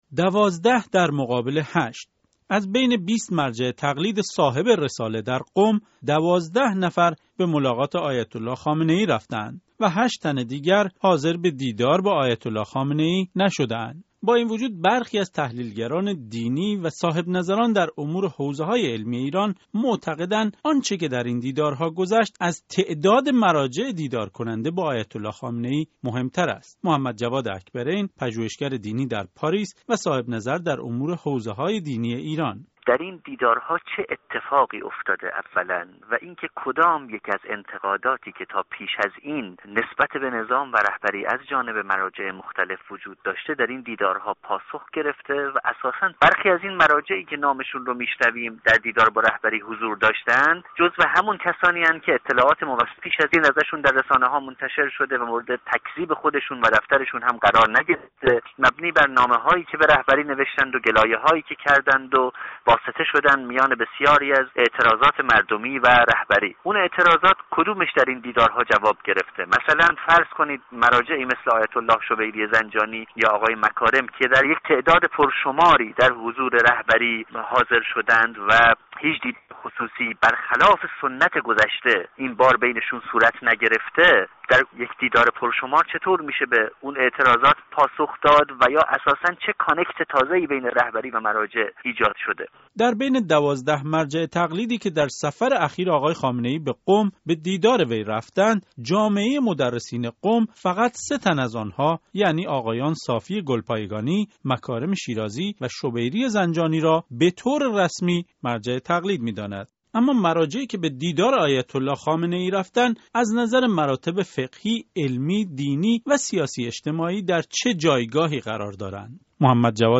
بررسی دیدارهای آیت‌آلله خامنه‌ای با مراجع قم در گفت‌وگو